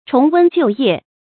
重溫舊業 注音： ㄔㄨㄙˊ ㄨㄣ ㄐㄧㄨˋ ㄧㄜˋ 讀音讀法： 意思解釋： 謂再做以前曾做的事。